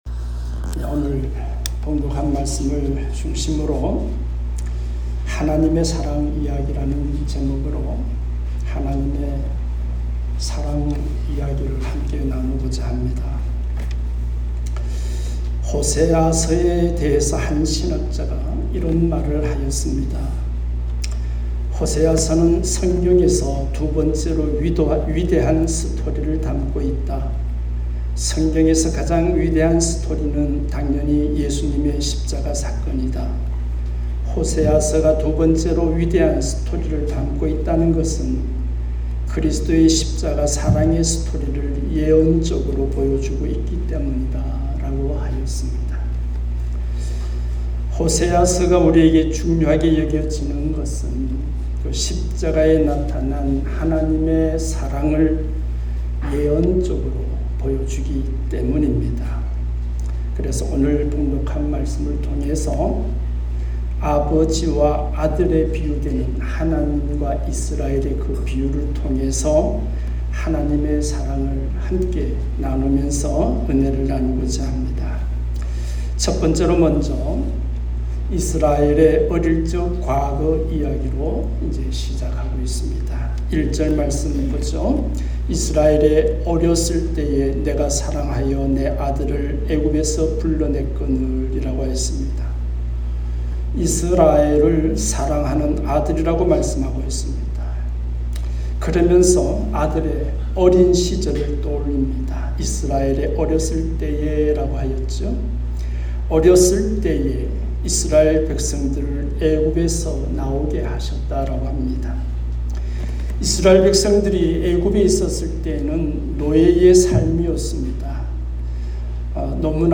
하나님의 사랑 이야기 ( 호11:1-9 ) 말씀